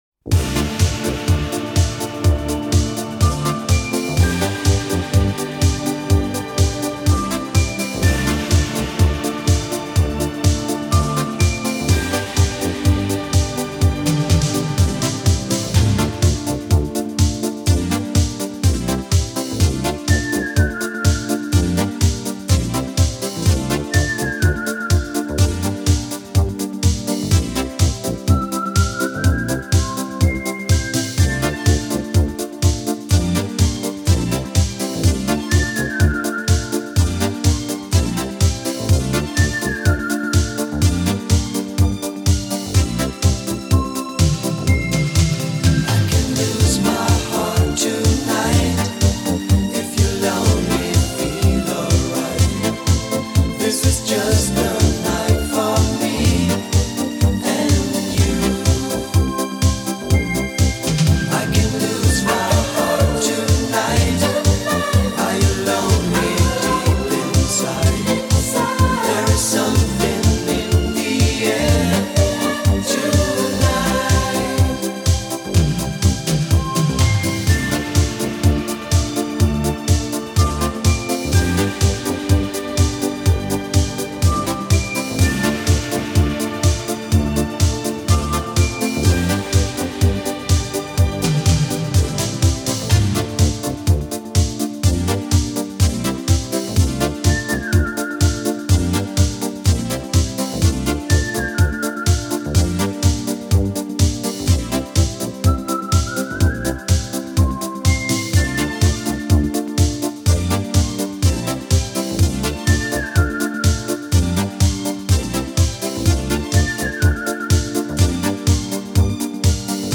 KARAOKE版